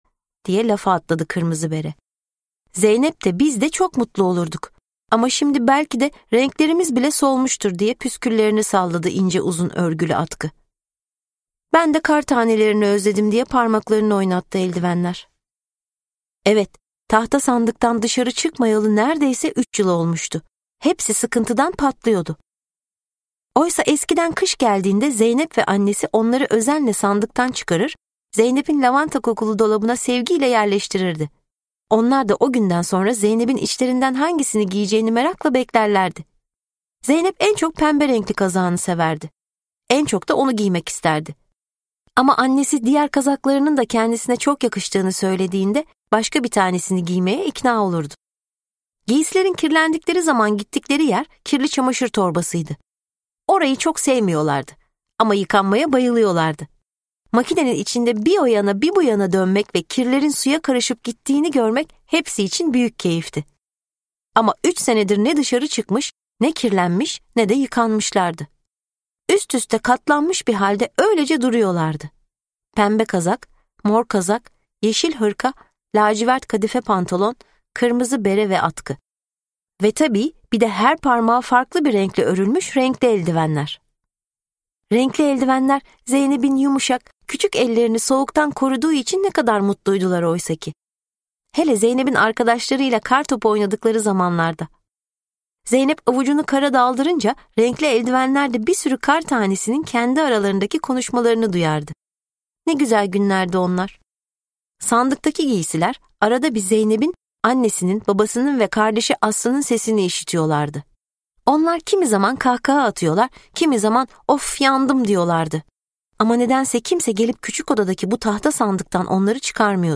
Renkli Eldivenler ve Küçük Kar Tanesi - Seslenen Kitap